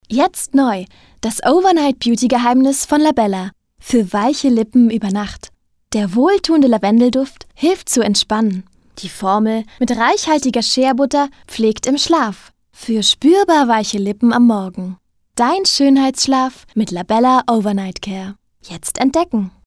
Meine Stimme – klar, warm, wandelbar.
In meinem eigenen Studio entstehen hochwertige Aufnahmen – effizient, professionell und mit viel Herz für das gesprochene Wort.
Werbung – Beauty – Lippenpflege
Werbung_Lippenpflege.mp3